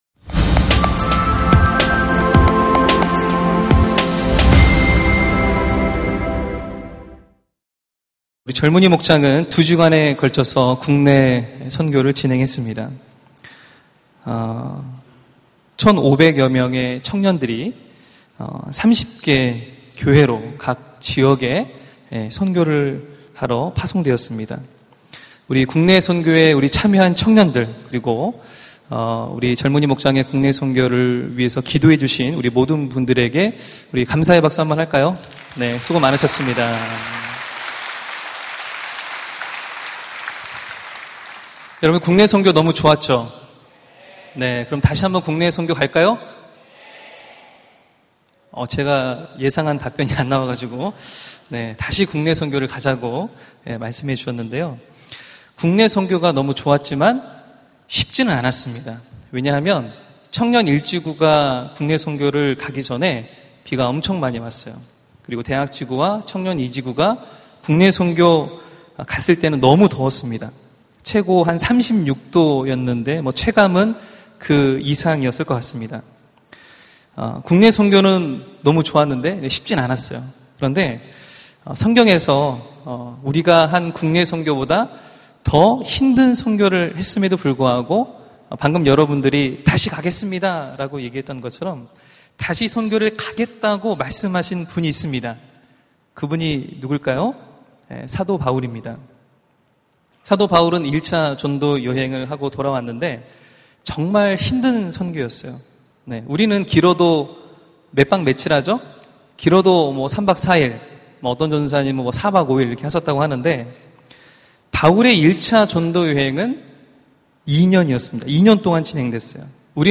설교 : 파워웬즈데이 하나님으로부터 위대한 일을 기대하라!